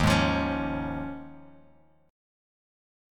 D#9sus4 Chord
Listen to D#9sus4 strummed